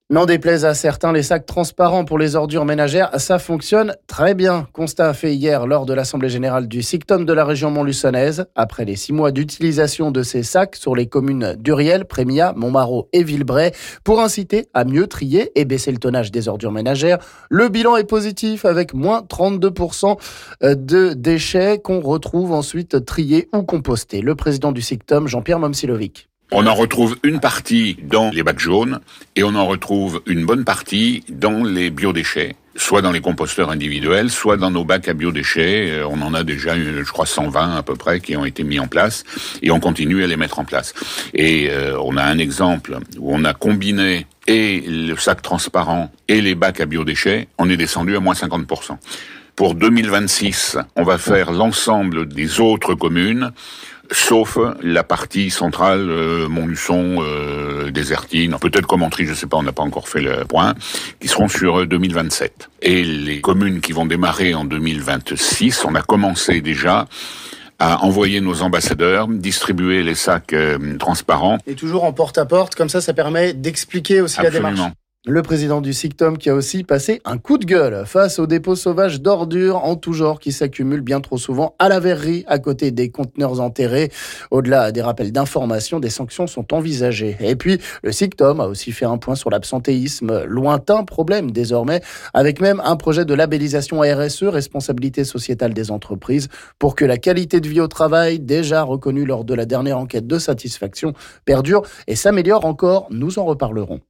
Le président du SICTOM Jean-Pierre Momcilovic nous en parle...